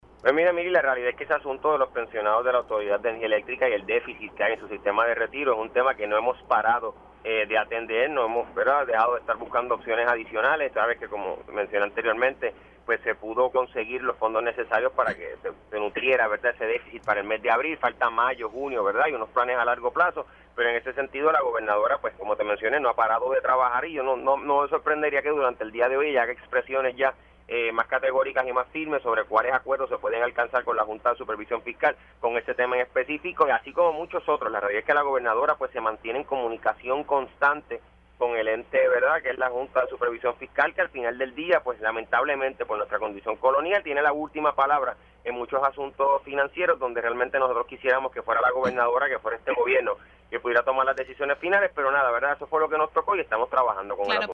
El secretario de Asuntos Públicos, Hiram Torres Montalvo, confirmó en Pega’os en la Mañana que hoy, viernes, la gobernadora Jenniffer González Colón hará expresiones sobre el particular.